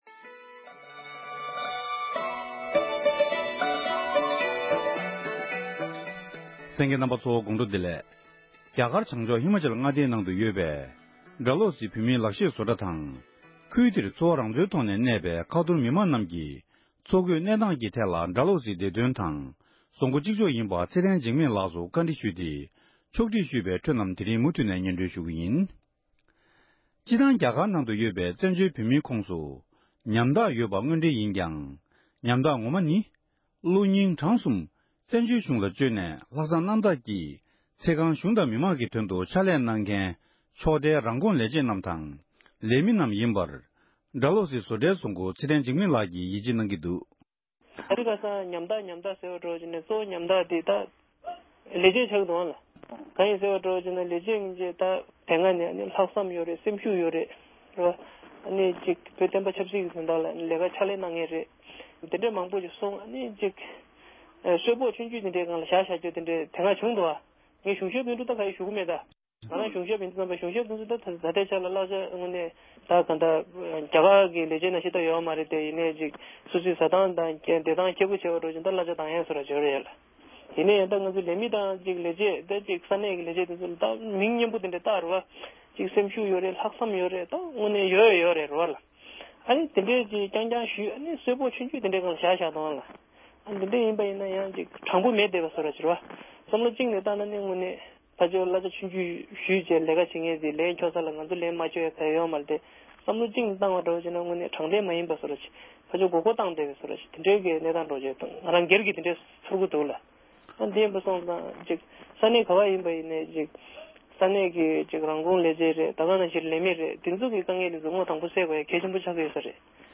གནས་འདྲི་ཞུས་པའི་ལེ་ཚན་གསུམ་པར་གསན་རོགས༎